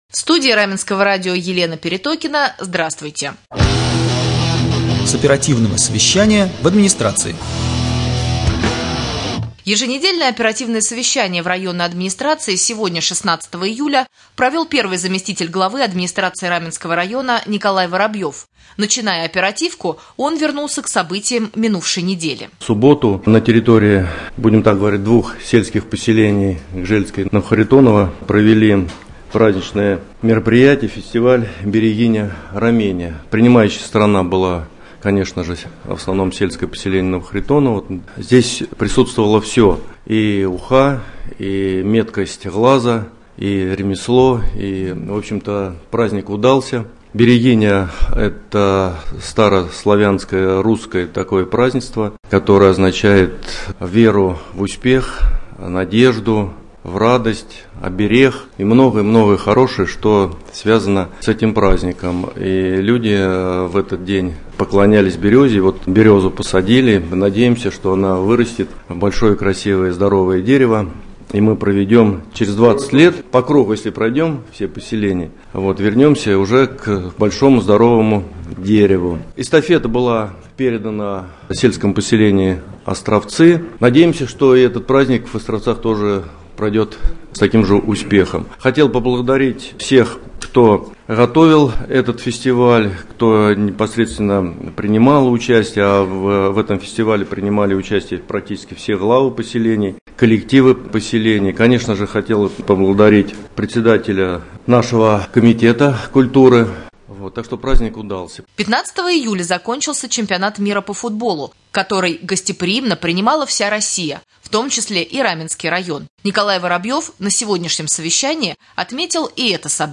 16.07.2018г. в эфире Раменского радио - РамМедиа - Раменский муниципальный округ - Раменское